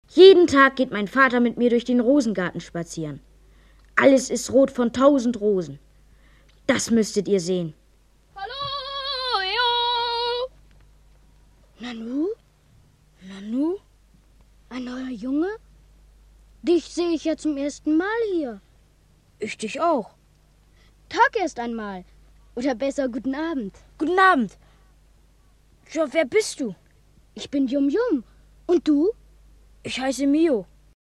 Der Waisenjunge Bo Vilhelm Olsson, der bei Pflegeeltern in liebloser Umgebung aufwächst und sich nach Verständnis und Geborgenheit sehnt, findet auf geheimnisvolle Weise das "Land der Ferne", in dem sein Vater, den er noch nie gesehen hat, König ist und er selbst als Prinz Mio ein vom Kampf gegen das Böse erfülltes Leben führt. Hörspiel von Radio Bremen.